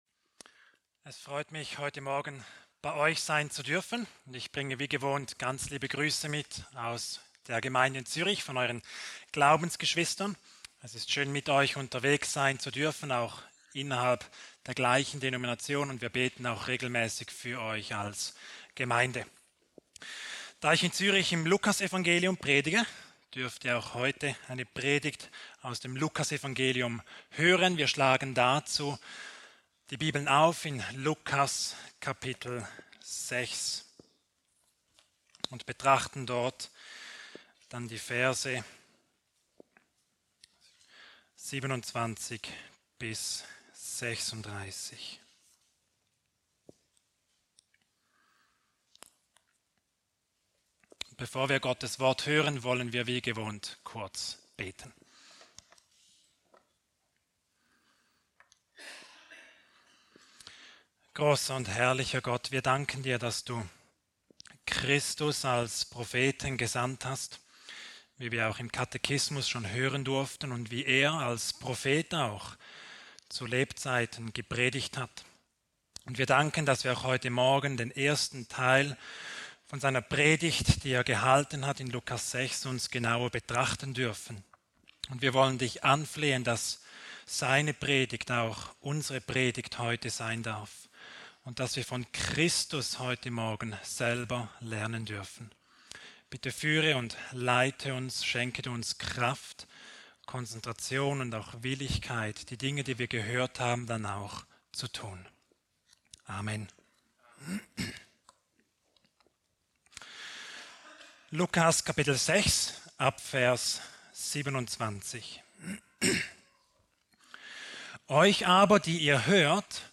Predigt aus der Serie: "Weitere Predigten"